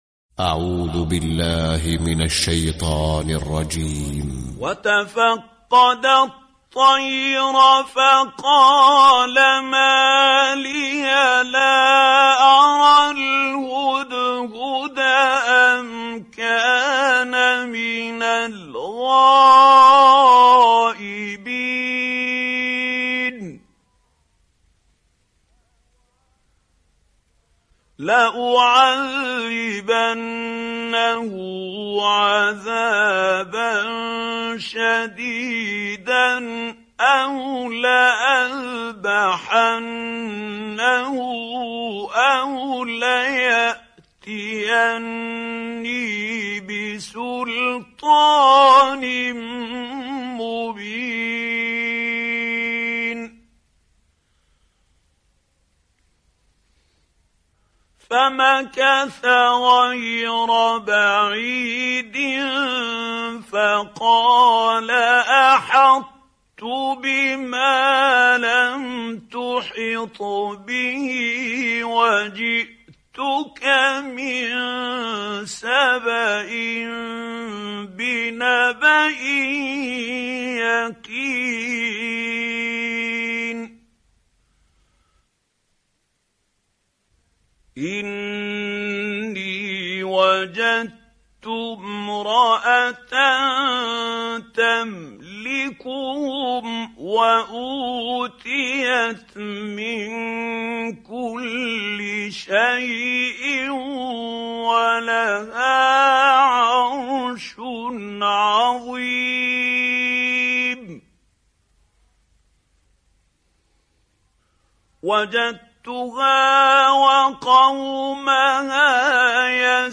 🌾💛•تلاوة مميزة•💛🌾
👤القارئ : محمود الحصري